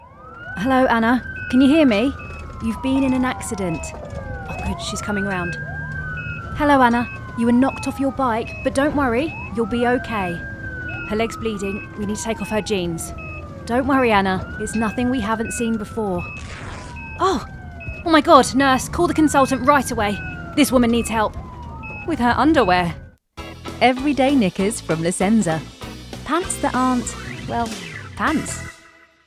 Funny Humour Stern Nurse
RP ('Received Pronunciation')